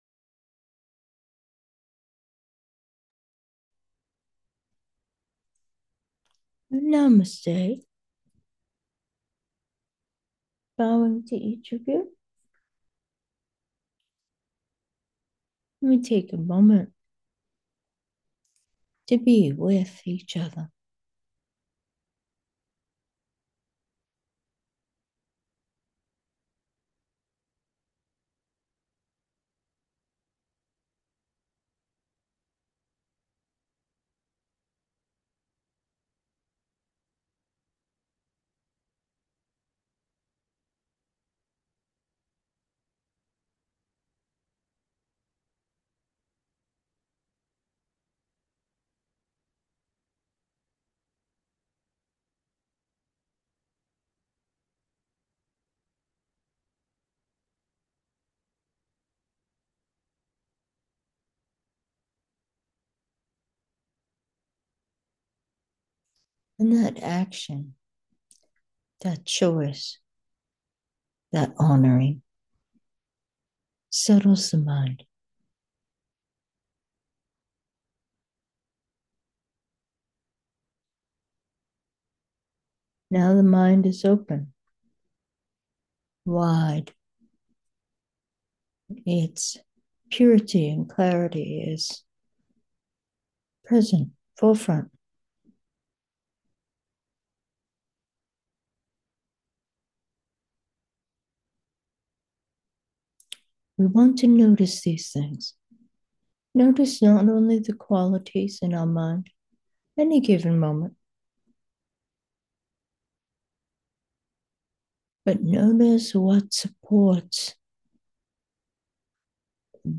Meditation: a peaceful mind